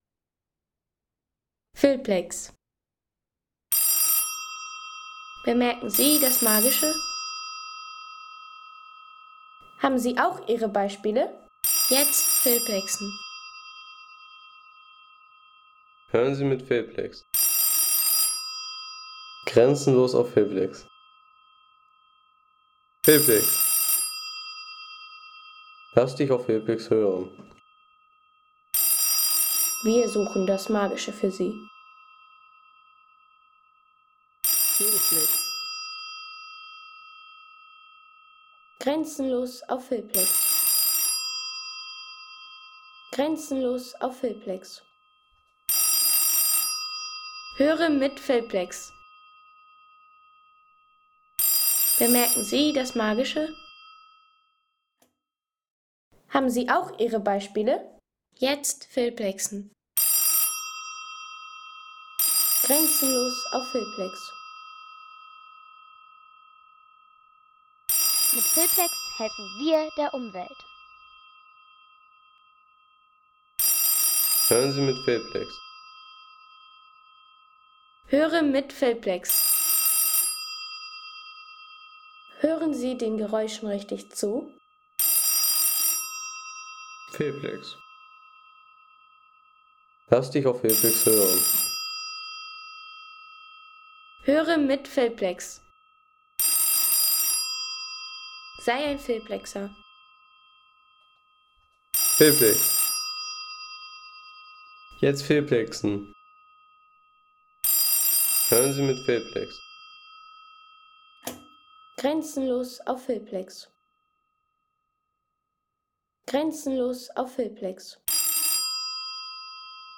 Telefontöne W28 - Siemens und Hals
Tischfernsprecher W28 – Siemens und Halske.